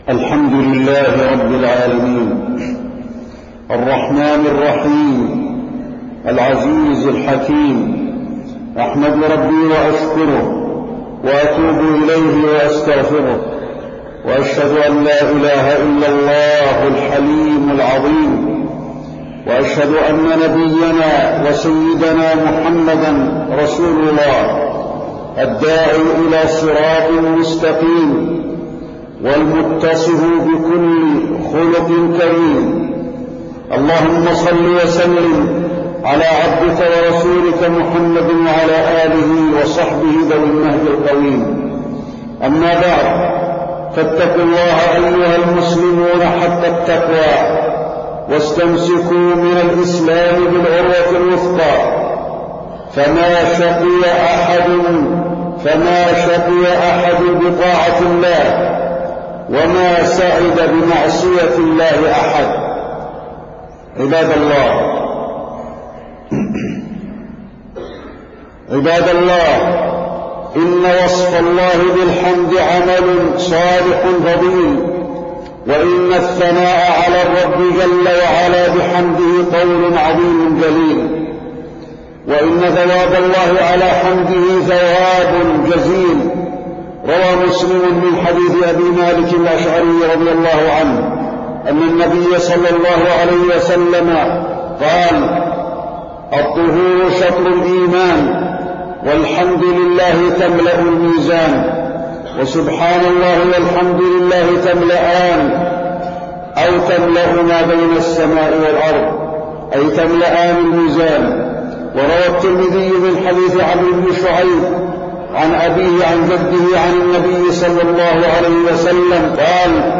تاريخ النشر ١٤ شوال ١٤٢٨ هـ المكان: المسجد النبوي الشيخ: فضيلة الشيخ د. علي بن عبدالرحمن الحذيفي فضيلة الشيخ د. علي بن عبدالرحمن الحذيفي فضل الحمد وشكر الله